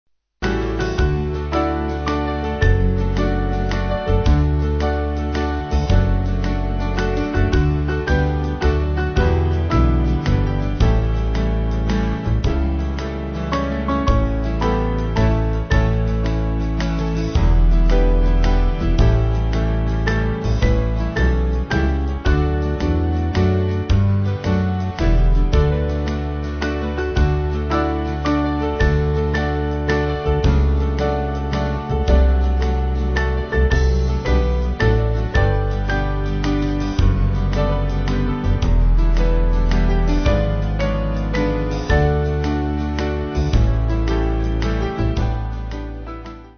Small Band
(CM)   2/D-Eb